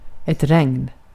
Ääntäminen
IPA: /rɛŋn/